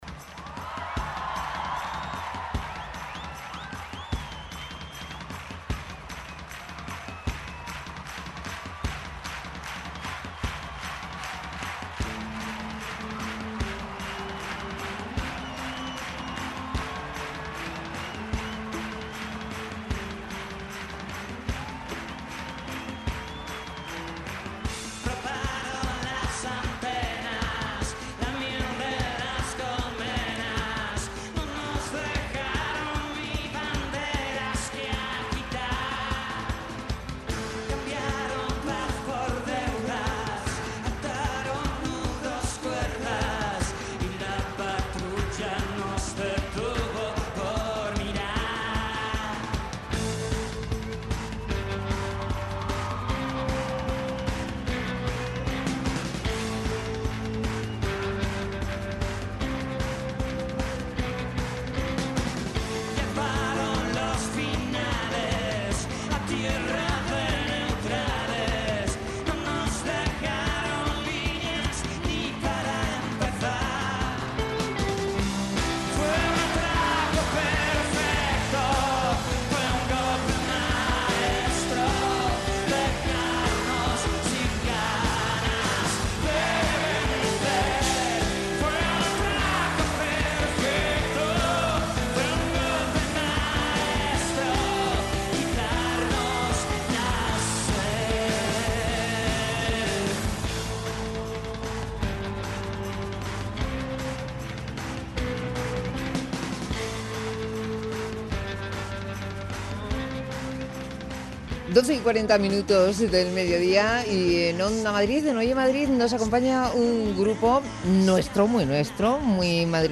Entrevista a Vetusta Morla